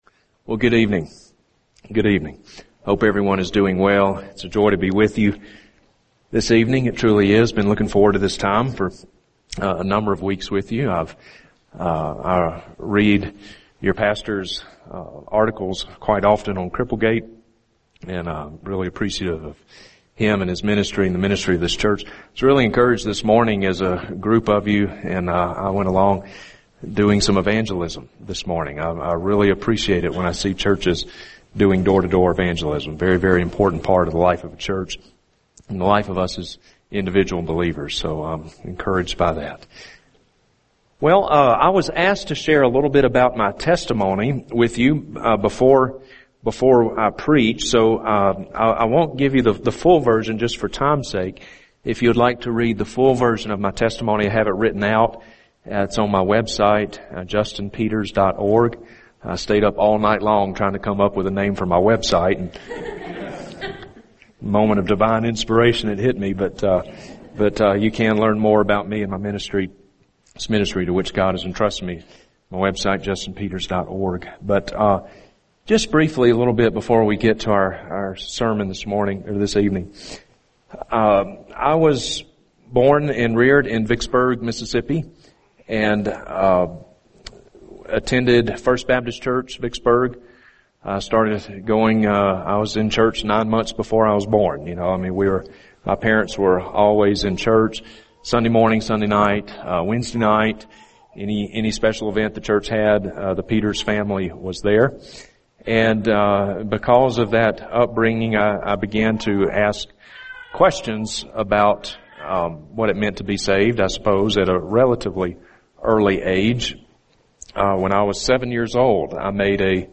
[sermon] Luke 16:19-31 – Sola Scriptura | Cornerstone Church - Jackson Hole
Followed by a Q&A session discussing the “Word of Faith” movement.